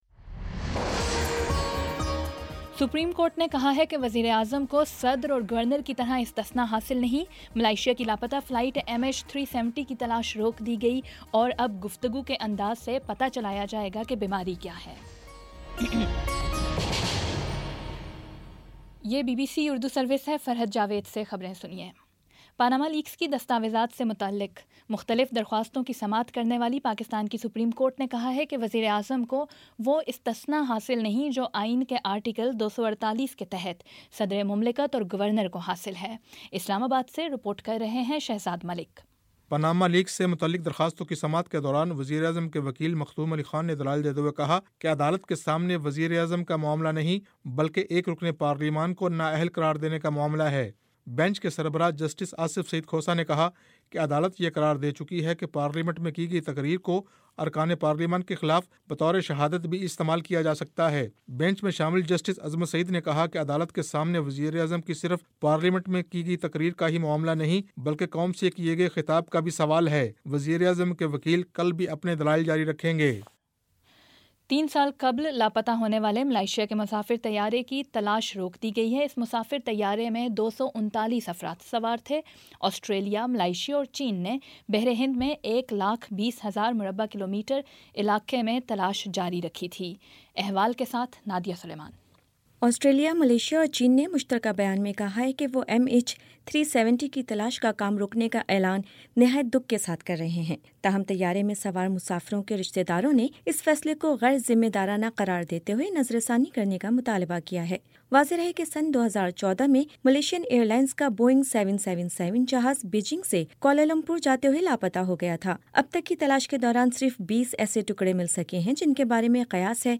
جنوری 17 : شام چھ بجے کا نیوز بُلیٹن